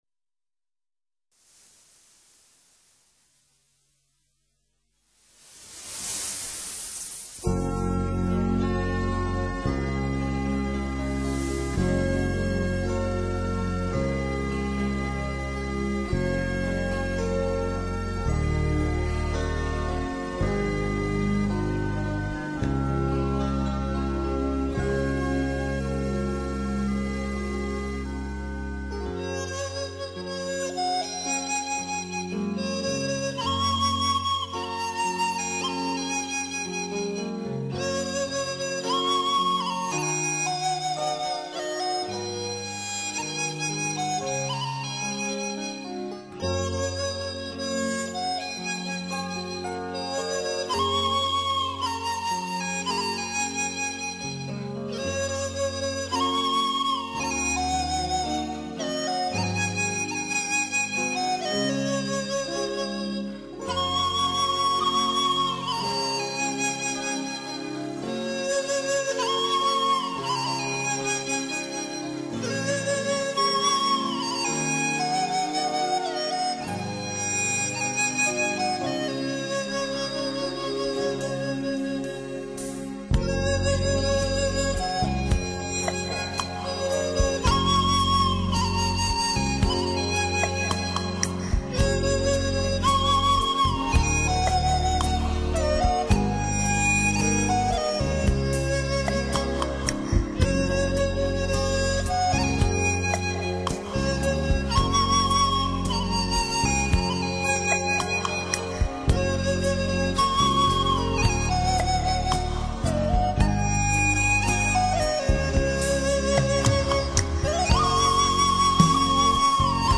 竹笛音质悠扬清脆，
适于表现婉转的情致和热烈欢快的情感，聆听吧！